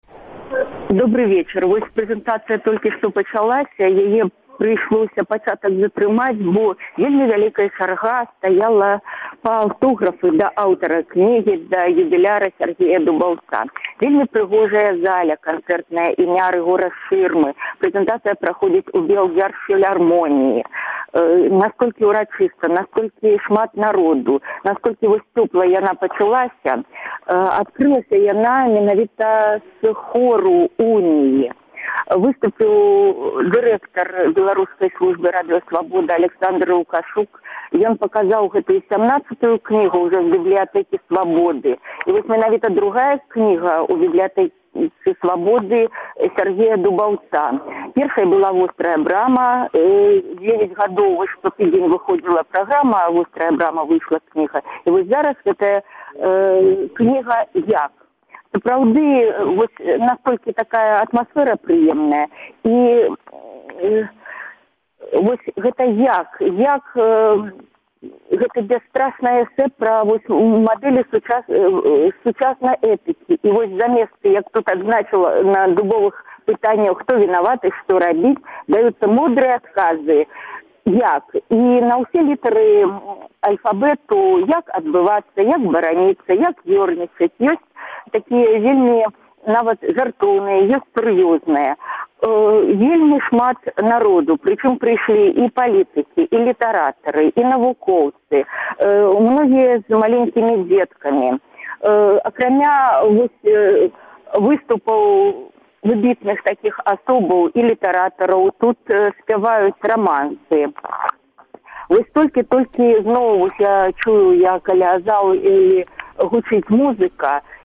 Рэпартаж